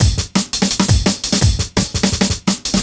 cw_170_SliceFunk2.wav